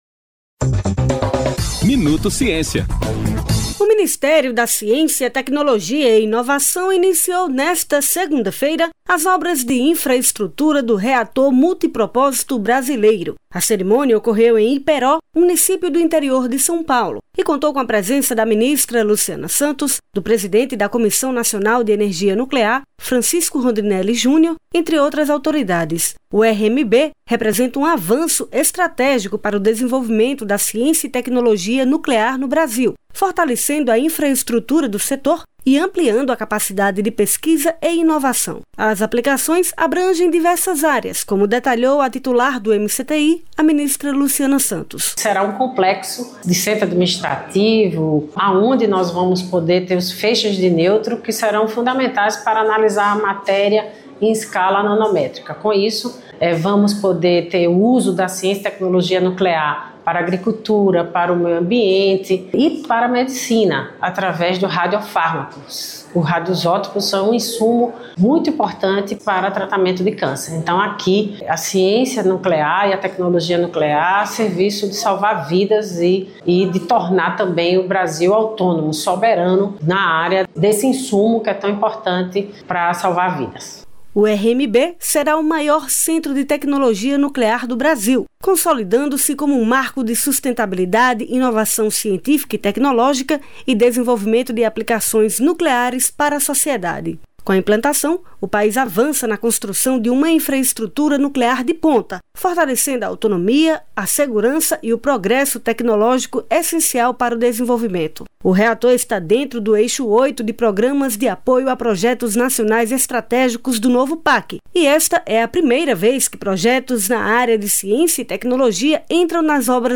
Boletim produzido pelo Ministério da Ciência, Tecnologia e Inovação (MCTI), com as principais informações do setor.